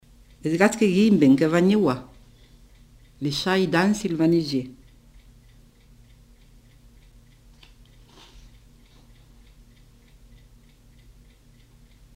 Lieu : Cathervielle
Genre : forme brève
Type de voix : voix de femme
Production du son : récité
Classification : proverbe-dicton